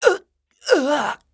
SFX player_dead.wav